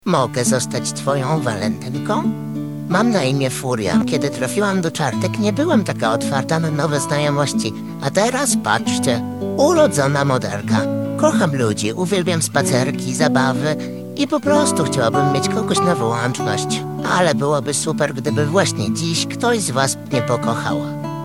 Udzielono im też głosu.